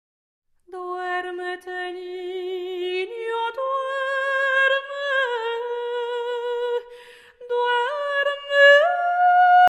chanteuse française